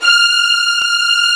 Index of /90_sSampleCDs/Roland LCDP13 String Sections/STR_Combos 2/CMB_Str.Orch Oct